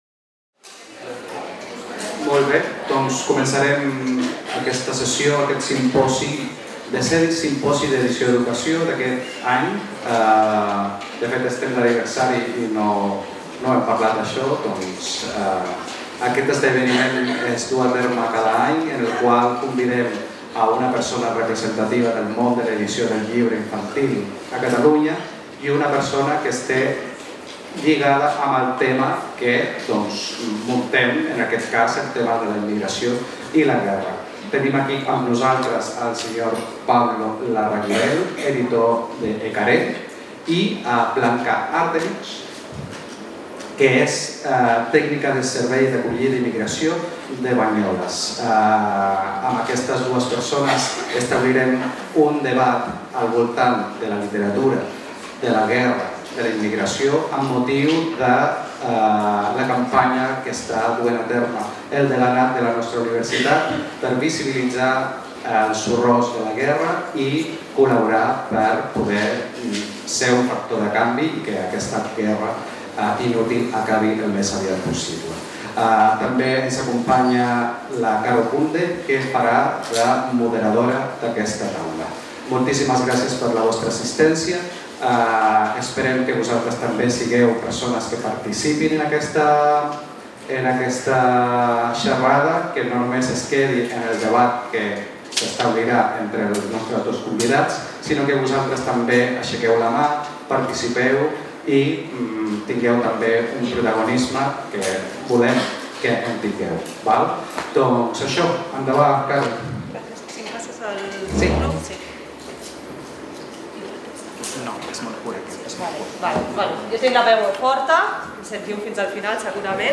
The symposium focused on war, immigration and diversity, with the aim of exploring how literature can address these global issues.
Emphasis was placed on the role of books in offering diverse perspectives and fostering a critical view of society, beyond media narratives. The symposium also questioned how to address these topics in schools and encouraged active audience participation.